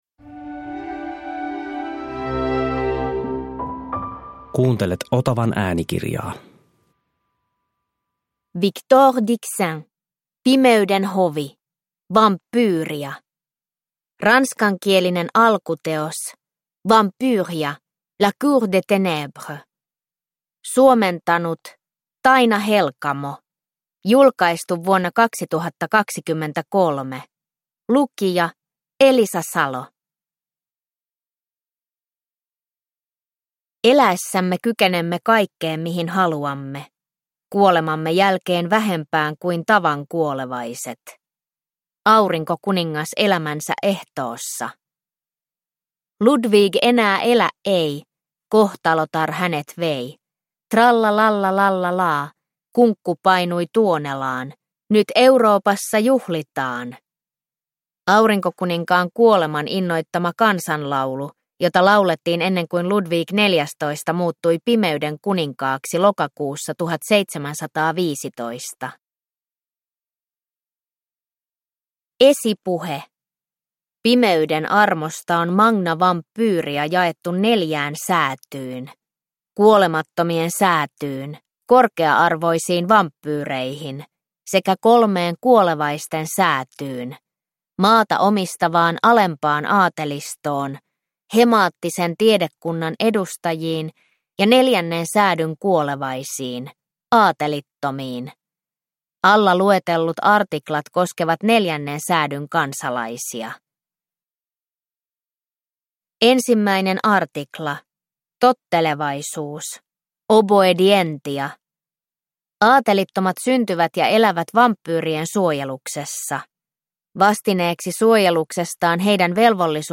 Pimeyden hovi – Ljudbok